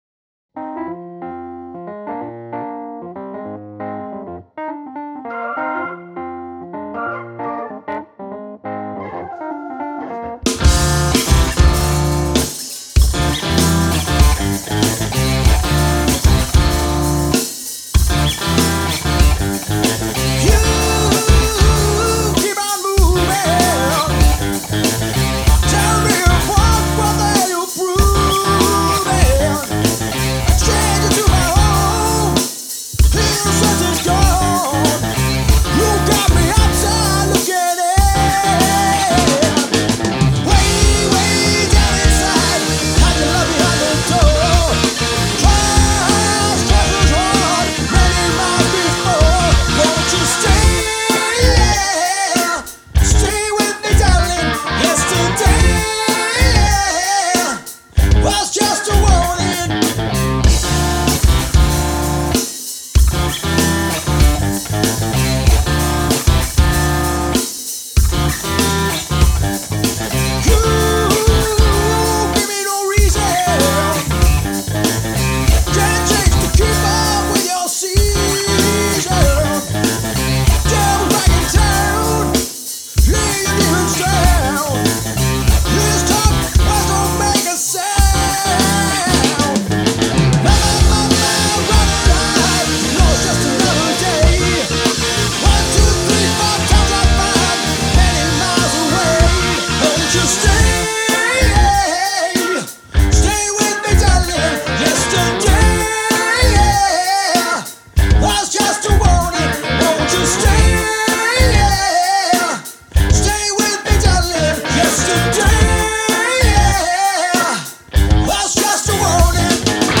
Guitars, Vocals & Bass
Hammond Organ, Wurlitzer & Fender Rhodes
blues-edged rock